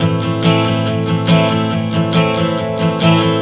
Strum 140 Am 03.wav